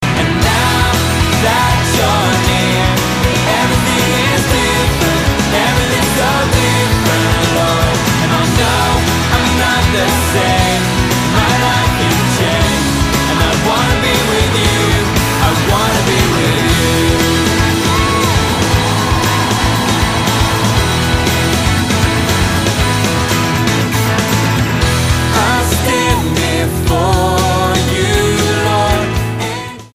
STYLE: Pop
a Brit-friendly sound